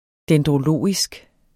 Udtale [ dεndʁoˈloˀisg ]